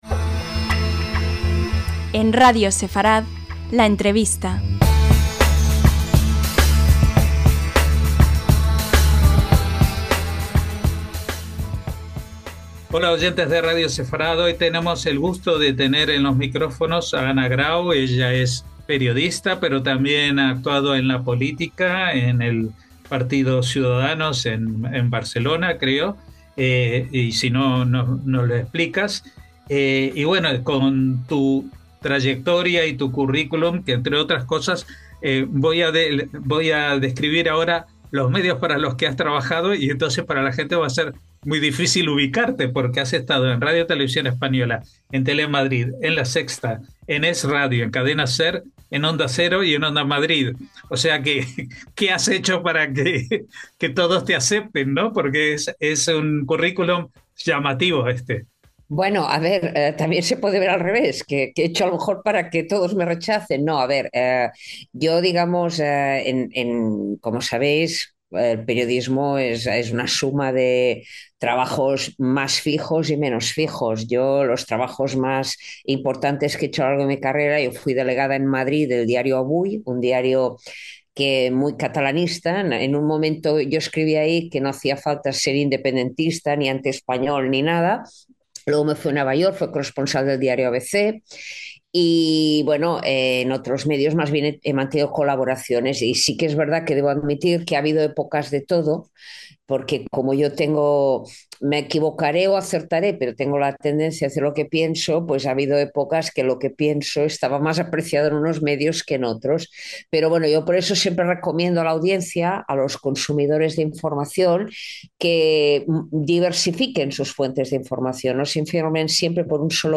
LA ENTREVISTA - Anna Grau es una periodista con un imponente currículum (entre otros como corresponsal en Madrid del diario barcelonés Avui o del periódico ABC en Nueva York). Pero también ha sido política con el partido Ciudadanos, además de ser autora de varios libros.